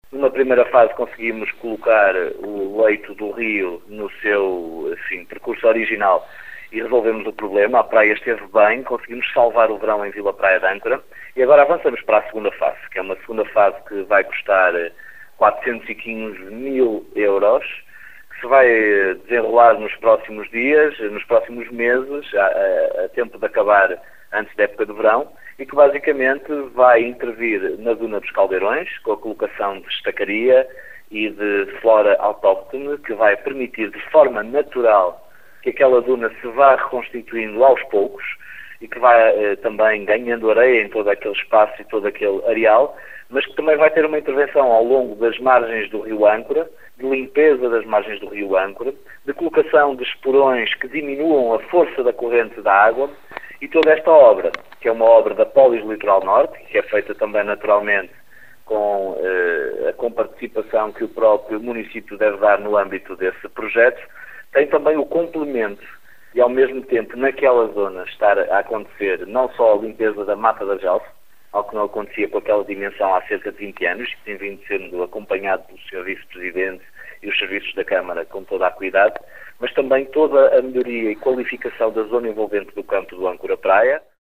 Começa esta sexta-feira a segunda fase das obras de recuperação da duna dos Caldeirões, em Vila Praia de Âncora, no concelho de Caminha. A empreitada visa “o reforço e a proteção dos sistemas dunares e a renaturalização de áreas degradadas da foz do rio Âncora”, como exlica o autarca Miguel Alves